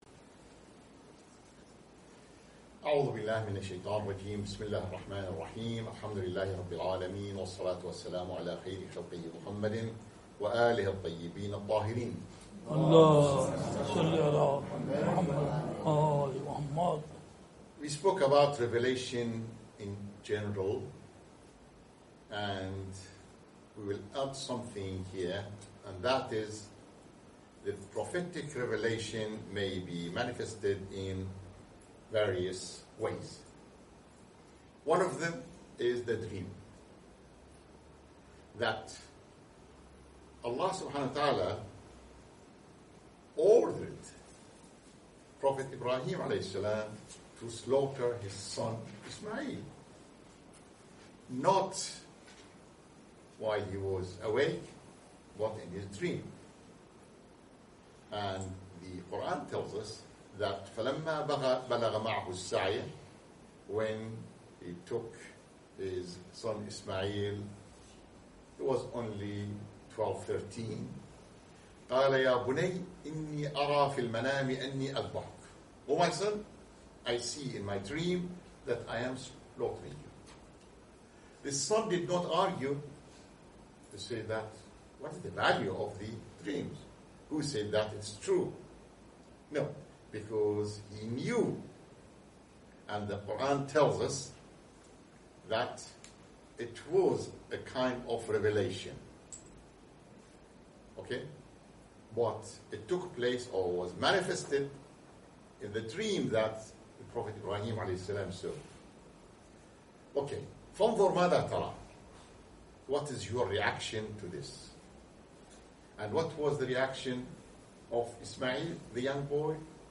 Prophetic revelation Theology lessons during the month of Ramadhan 2017 Lessons at the Khatam Al Nabaeen, London